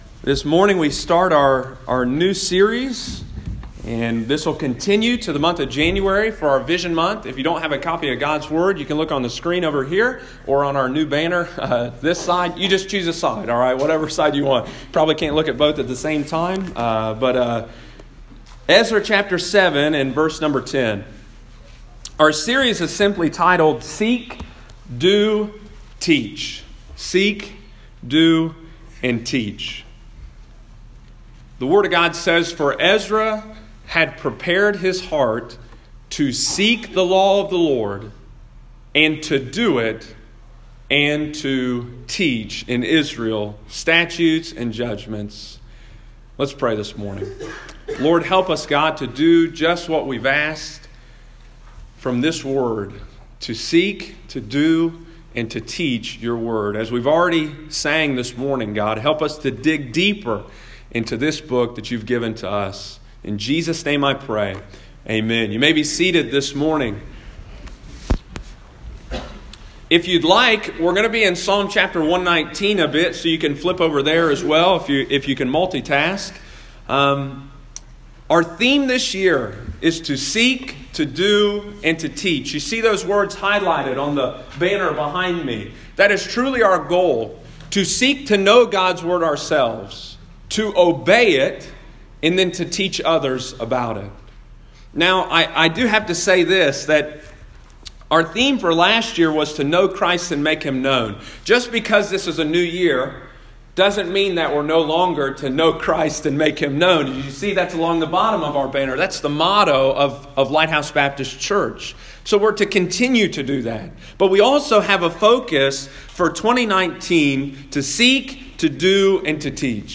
Sunday morning, December 30, 2018.